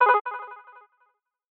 HTC Bildirim Sesleri
Ses çözünürlüğü 192 Kbps / 16 bits ve ses örnekleme oranı 48 Khz olarak üretilen sesler stereo ve yüksek ses çıkışına sahiptir.